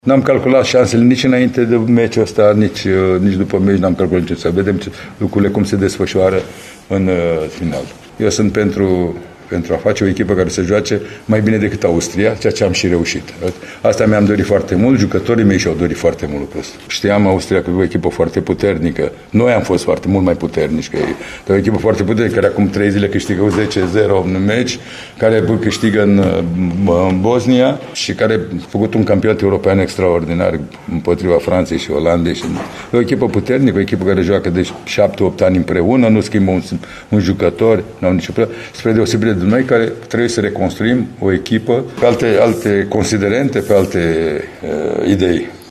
Selecționerul Mircea Lucescu spune că nu s-a gândit deloc la aceste scenarii, nici înaintea confruntării cu austriecii și nici imediat după fluierul final: